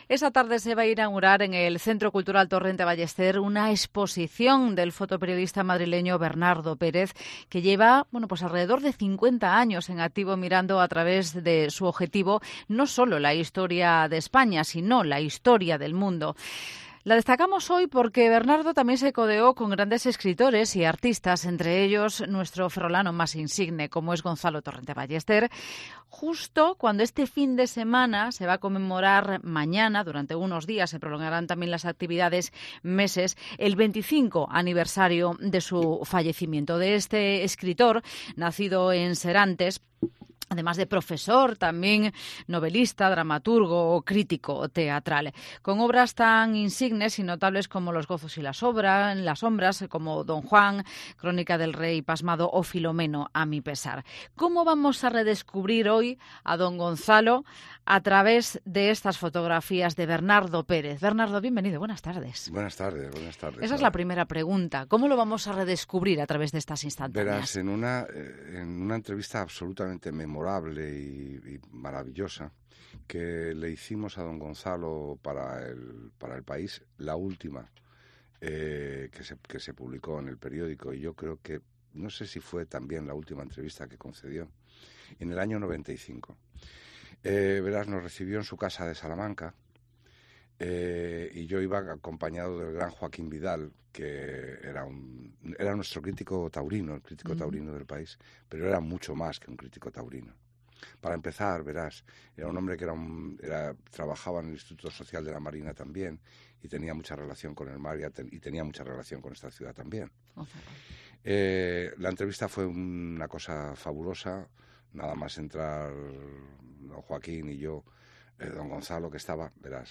en los estudios de COPE Ferrol